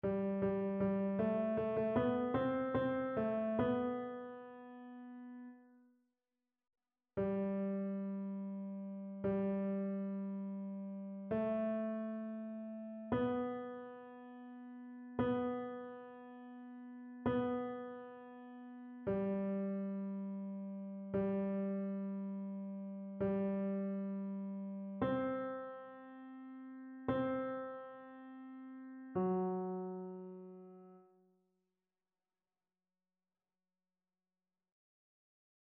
Ténor
annee-b-temps-ordinaire-23e-dimanche-psaume-145-tenor.mp3